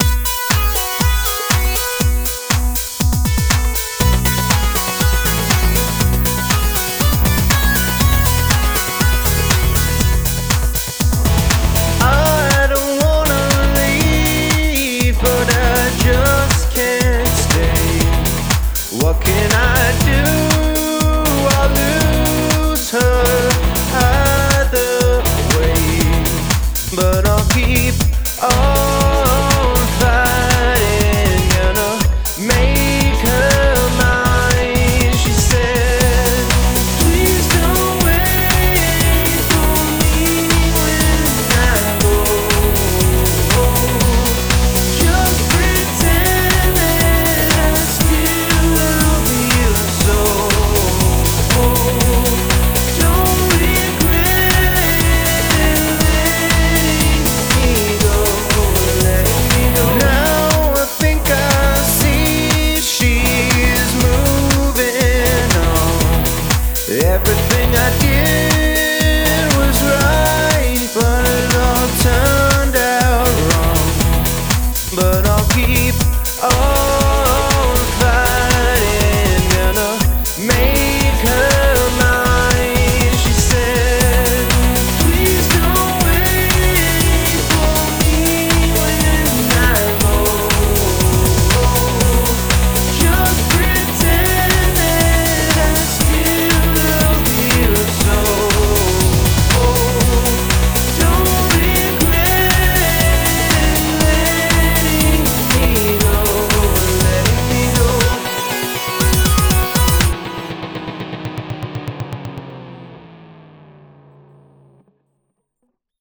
BPM120
Audio QualityMusic Cut